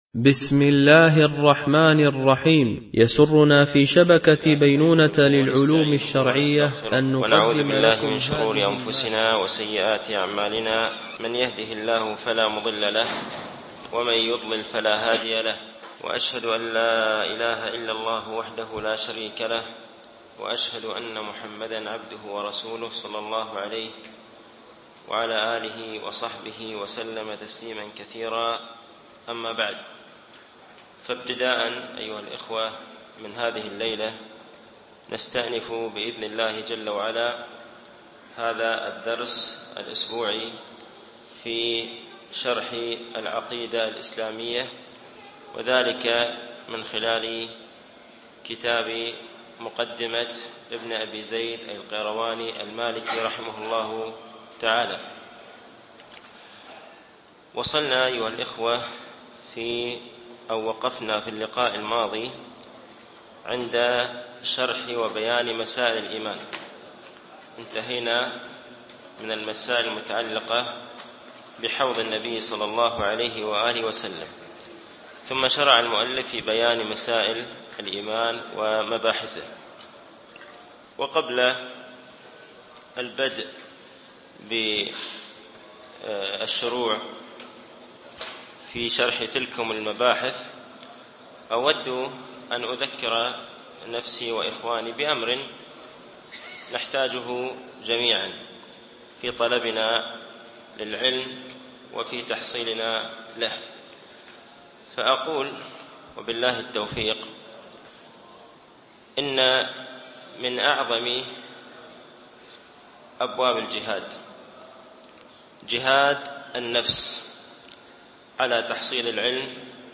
شرح مقدمة ابن أبي زيد القيرواني ـ الدرس الخامس و الخمسون